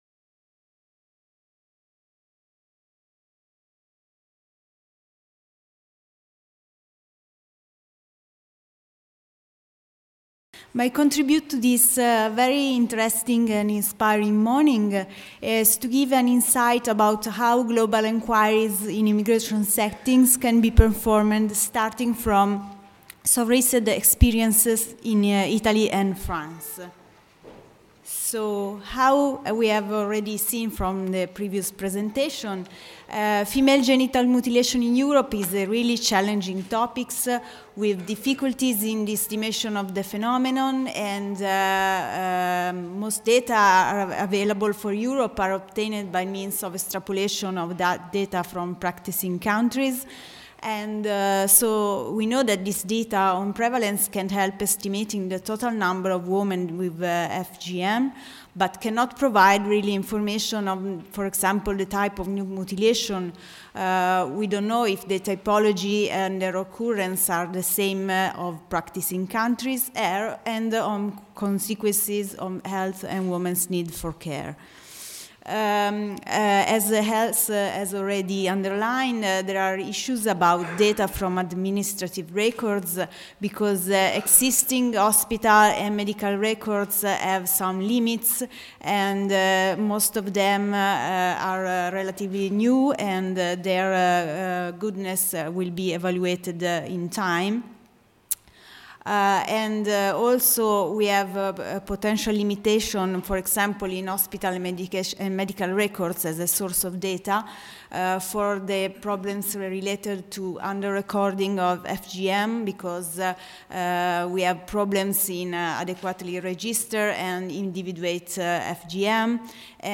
Intervention